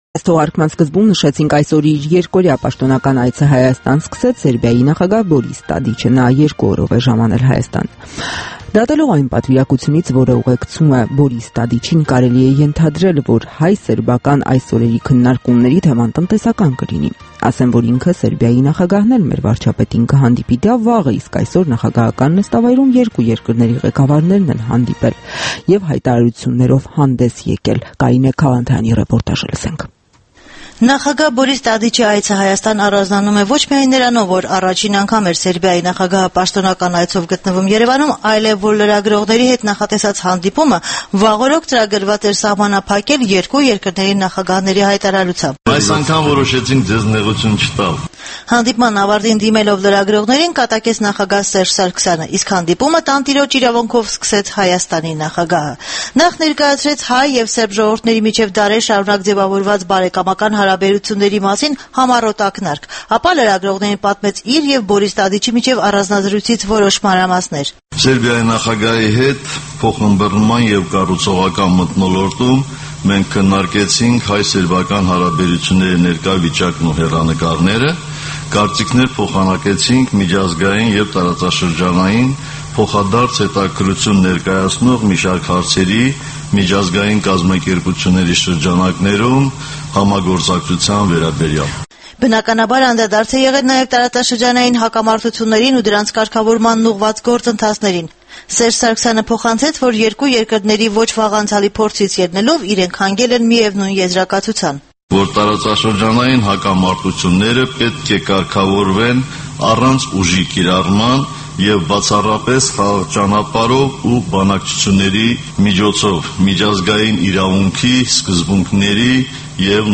Հայաստանի եւ Սերբիայի նախագահների համատեղ ասուլիսը